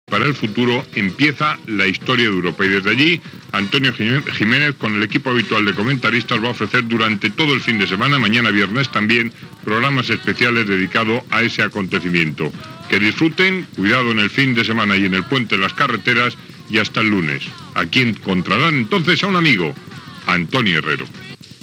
Comiat del programa.
Informatiu